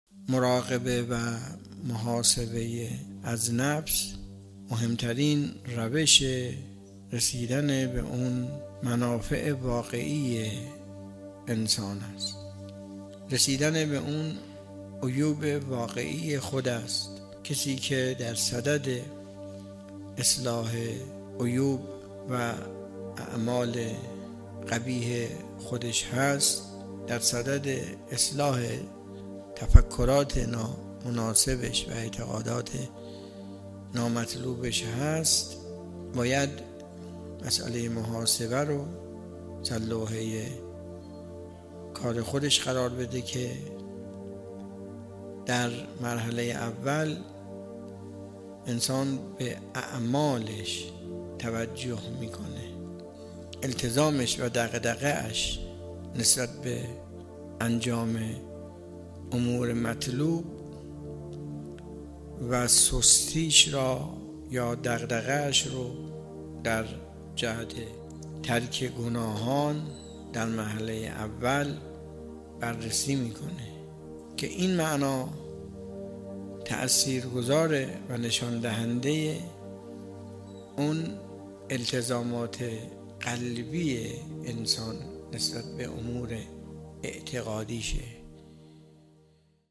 درس اخلاق | محاسبه نفس؛ نخستین گام اصلاح انسانیت